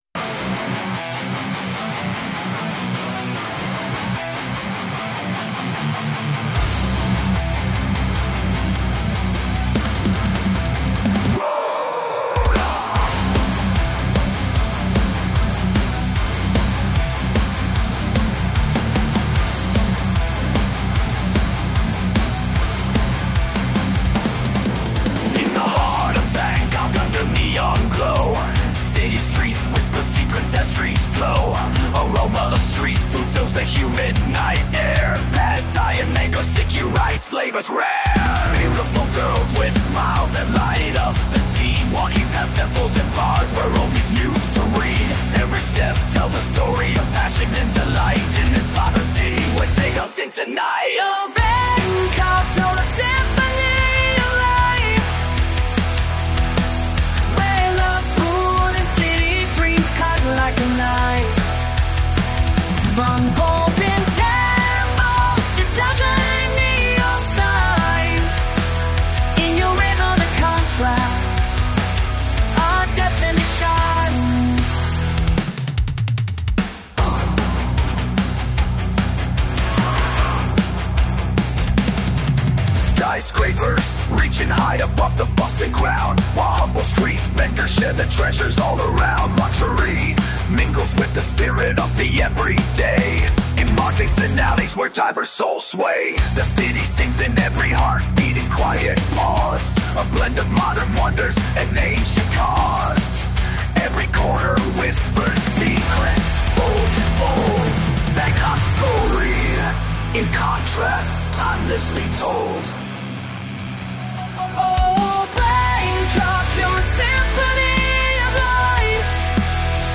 Den Frauengesang mitten drin hätte ich aber nicht gebraucht.